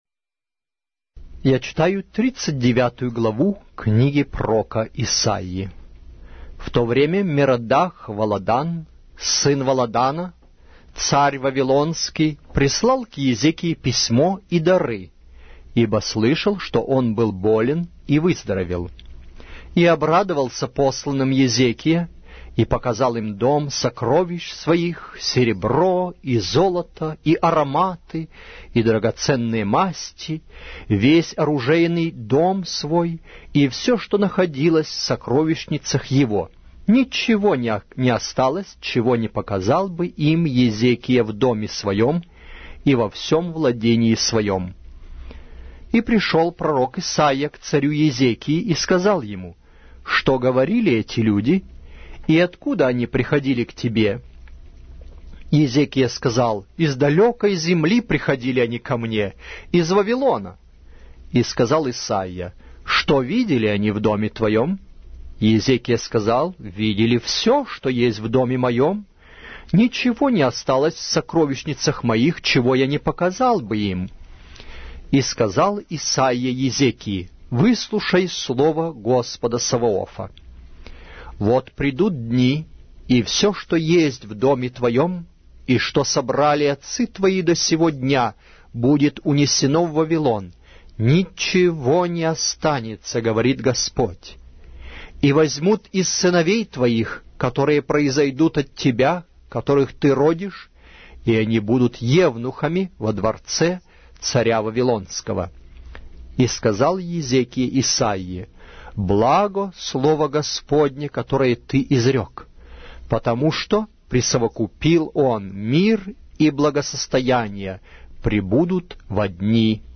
Аудиокнига: Пророк Исаия